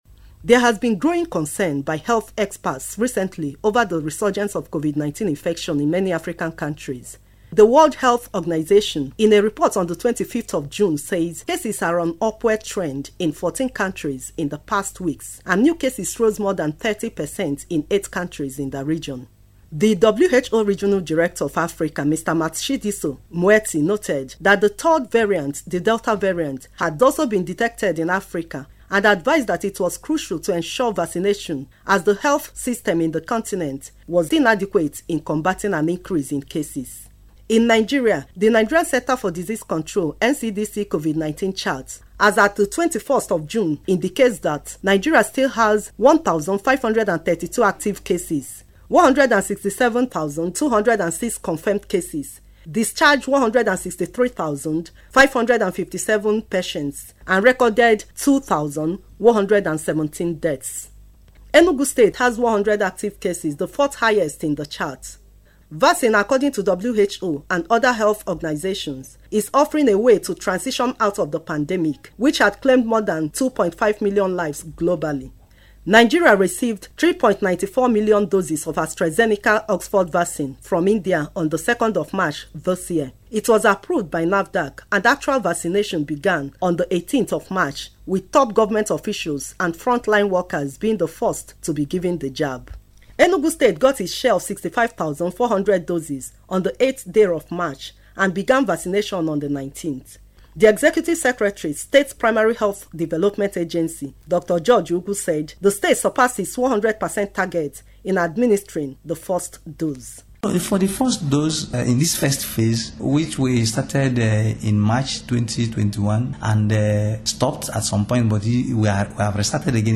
In this special report